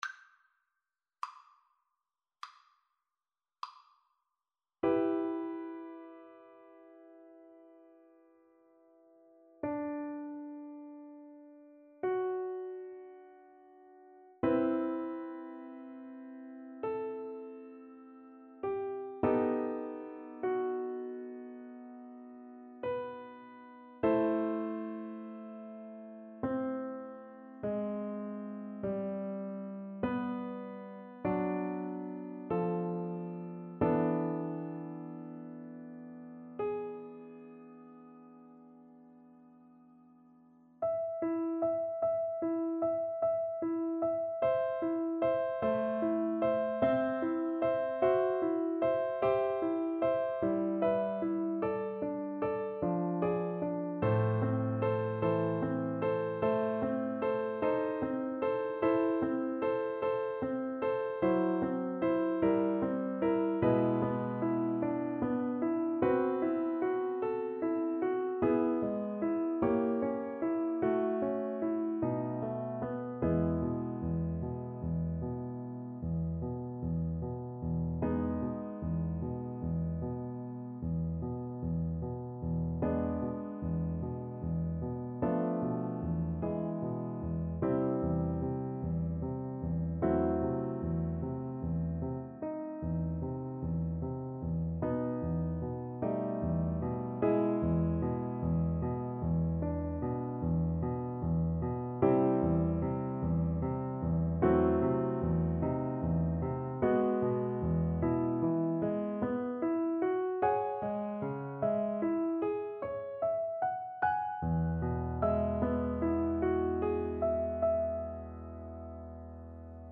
G major (Sounding Pitch) (View more G major Music for Viola )
~ = 100 Lento =50
Classical (View more Classical Viola Music)